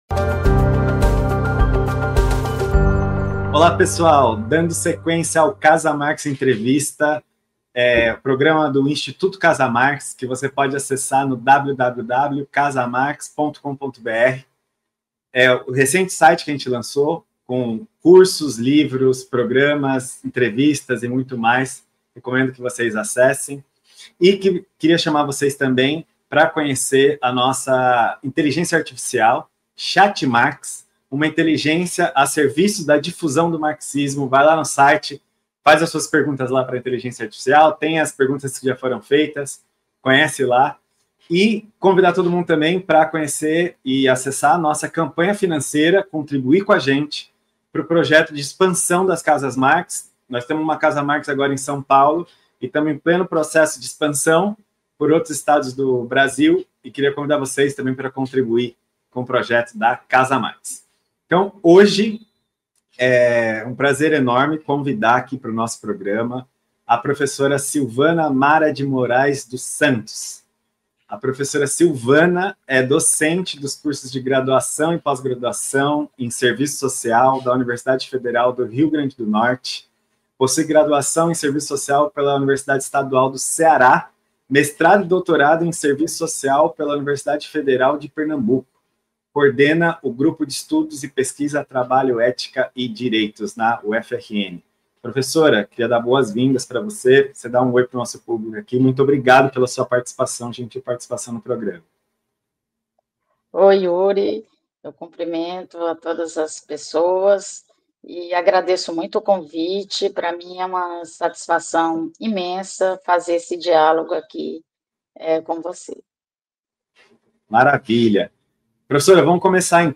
Marxismo e diversidade sexual | Entrevista